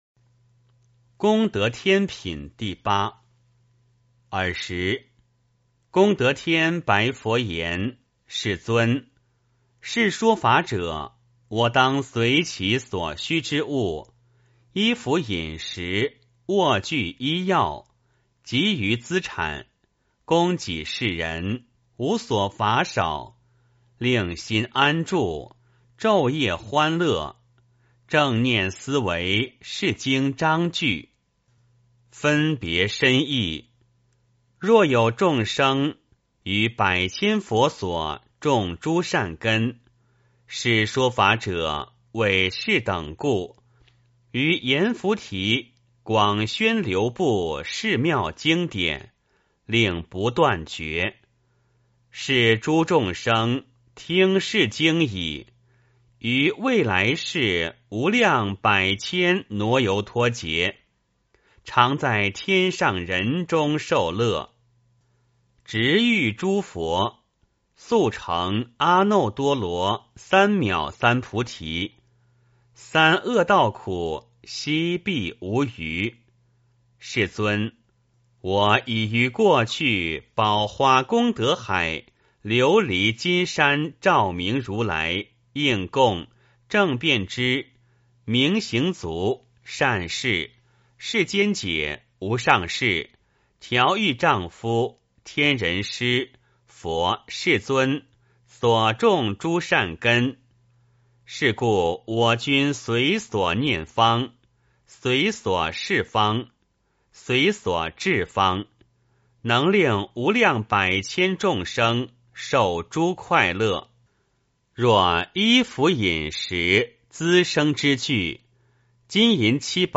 金光明经.功德天品第八 诵经 金光明经.功德天品第八--未知 点我： 标签: 佛音 诵经 佛教音乐 返回列表 上一篇： 金光明经.大辩天神品第七 下一篇： 金光明经-鬼神品第十三 相关文章 药师佛心咒--北京快乐小菩萨合唱团 药师佛心咒--北京快乐小菩萨合唱团...